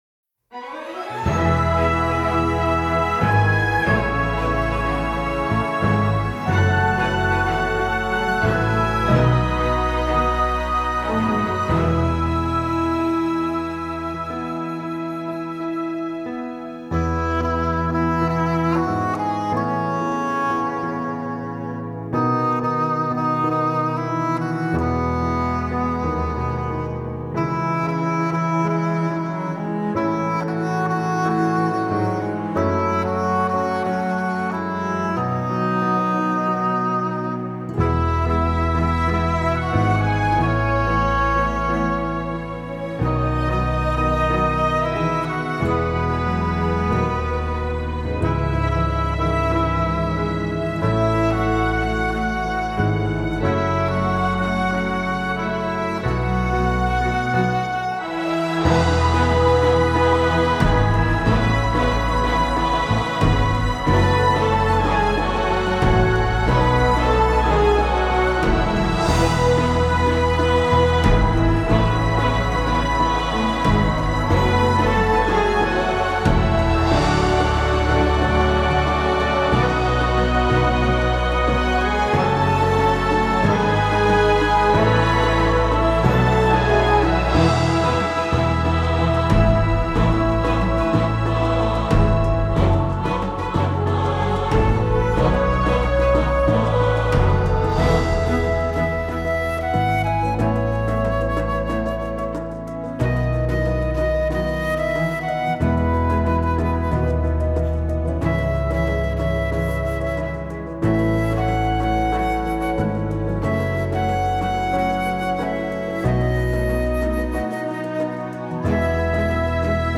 Orchestrated version for download: